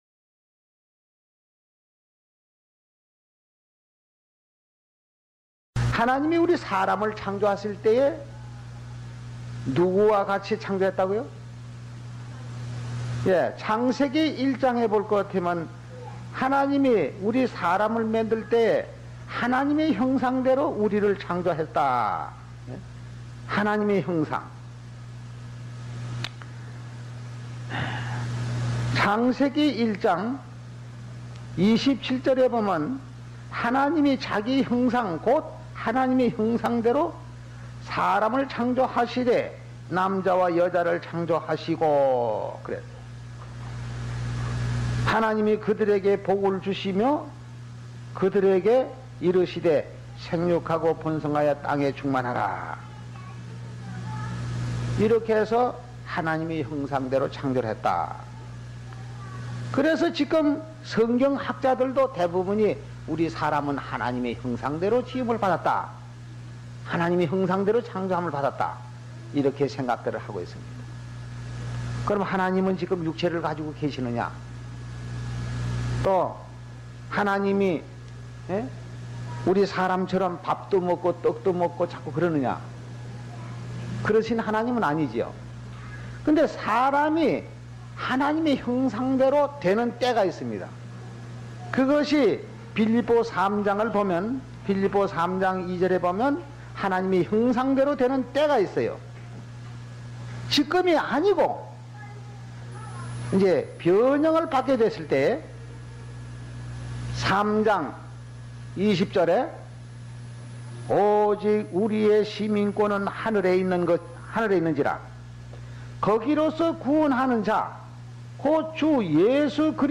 Sermon (Summary)